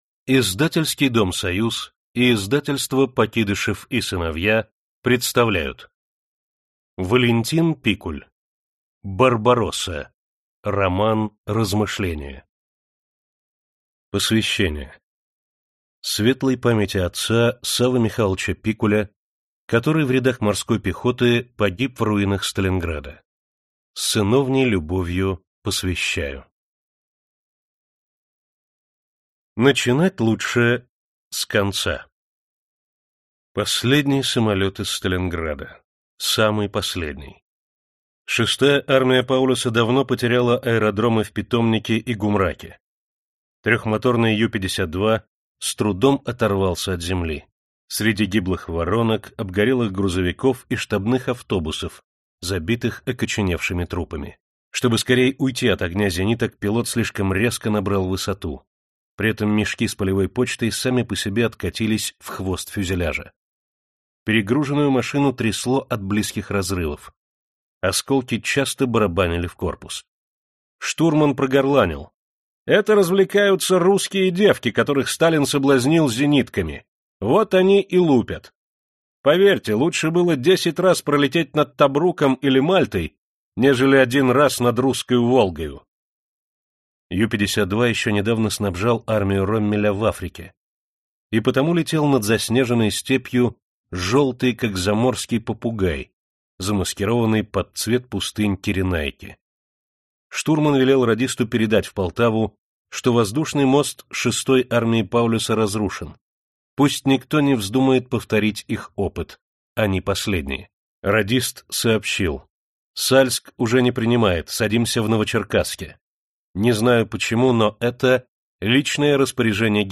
Аудиокнига Барбаросса. Часть 1. Большая стратегия | Библиотека аудиокниг